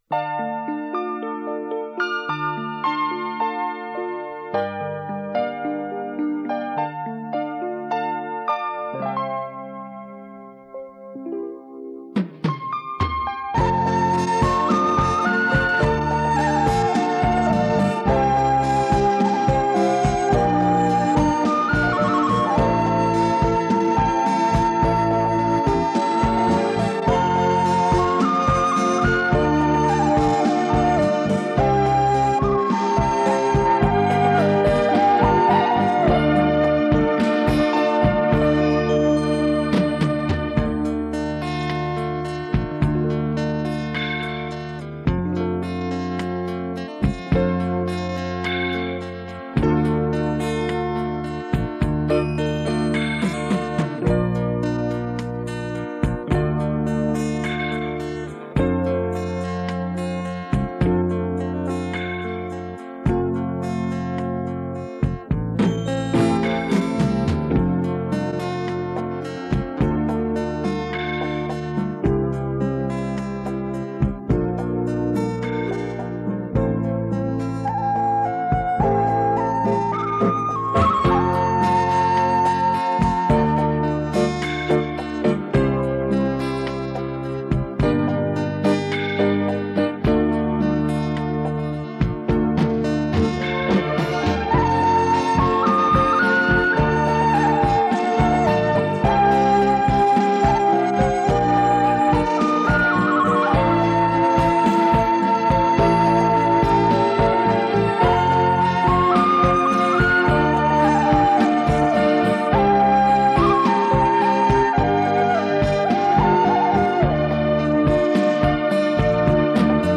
我们将stretch.wav降e调处理成f调.但是音乐的长度不变,他们之间的高度相差两个半音
stretch.wav